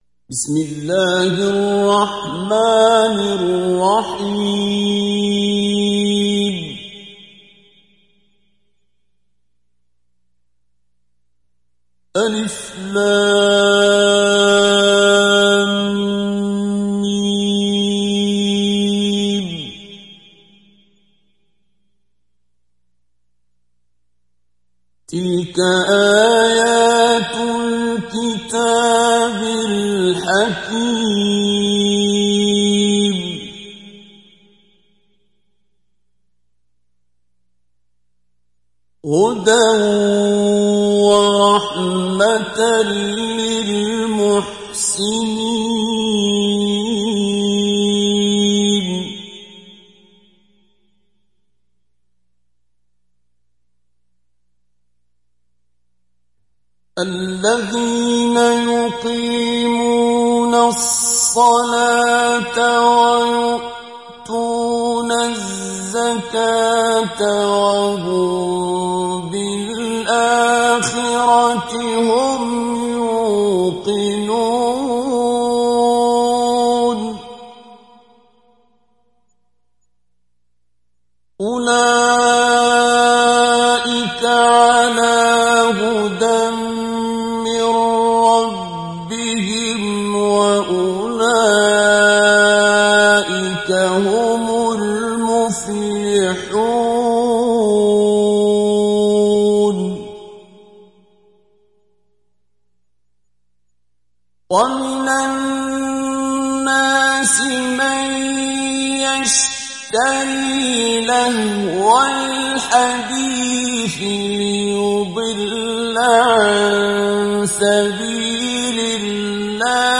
Sourate Luqman Télécharger mp3 Abdul Basit Abd Alsamad Mujawwad Riwayat Hafs an Assim, Téléchargez le Coran et écoutez les liens directs complets mp3
Télécharger Sourate Luqman Abdul Basit Abd Alsamad Mujawwad